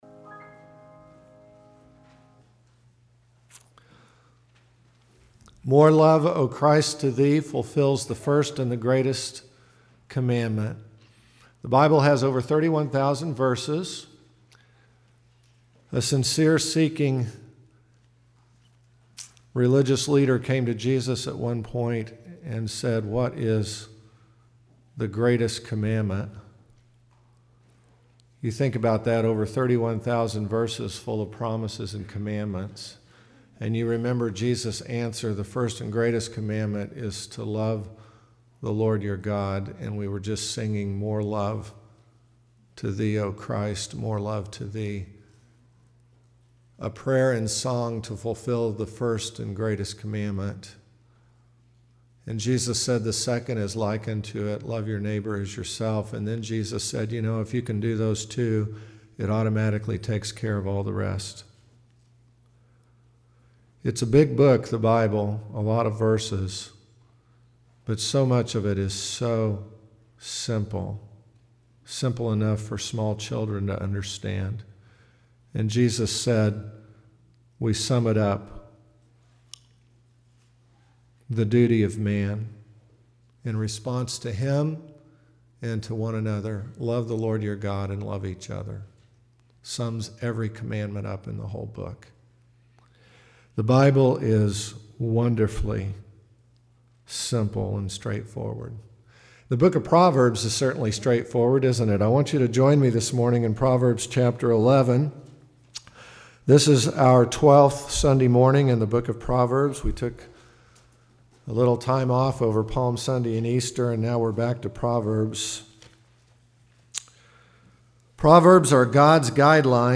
2017 Proverbs on Money Part 2 Preacher